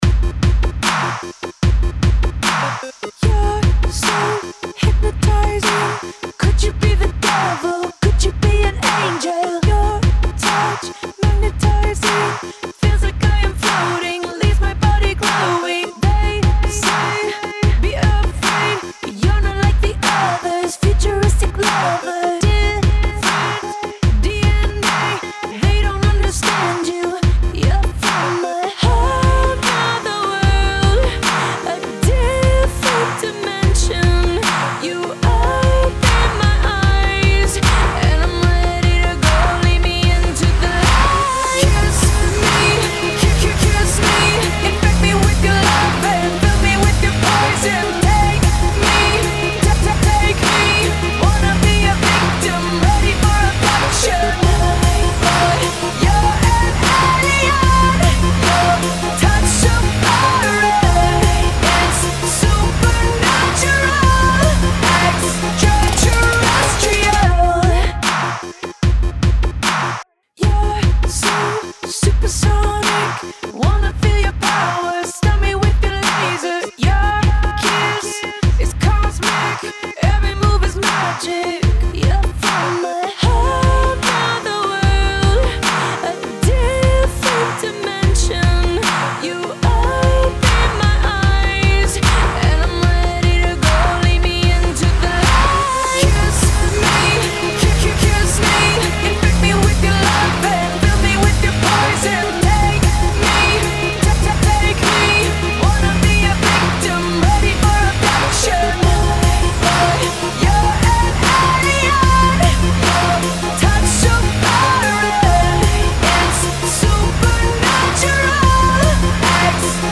Категория: Поп